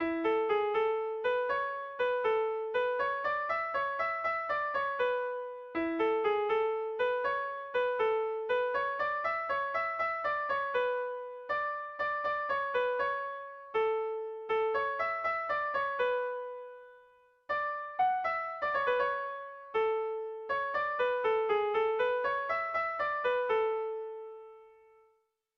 ABDE